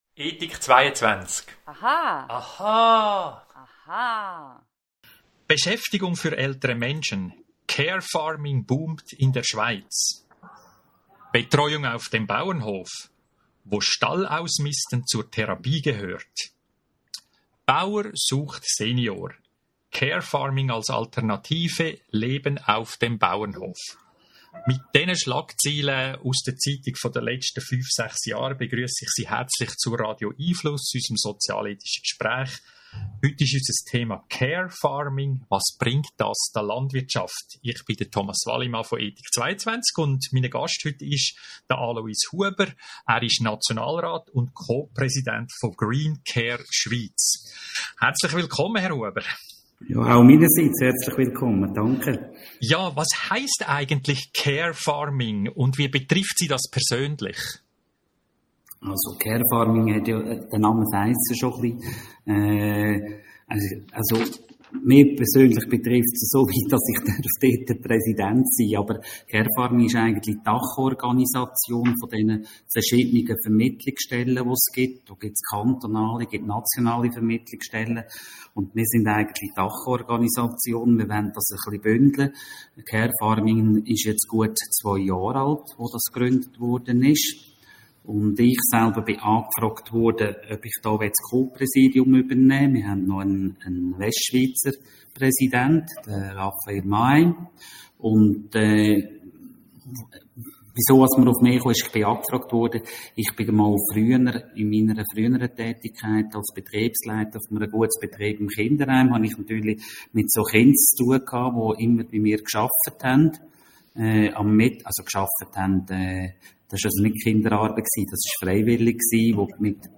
Radio🎙einFluss Audio-Gespräche informiert!